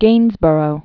(gānzbûrō, -bər-ə), Thomas 1727-1788.